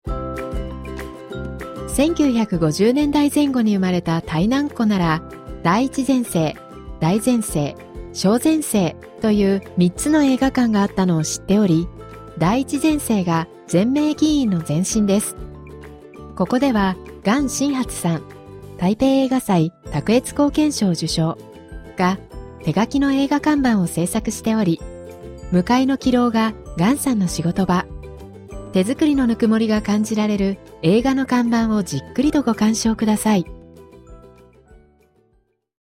日本語音声ガイド